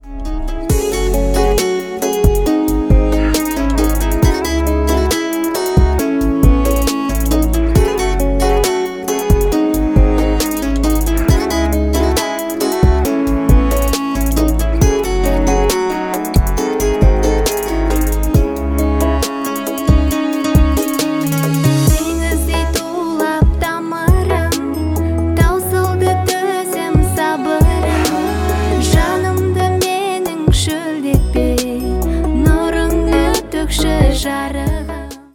казахские , кавер , поп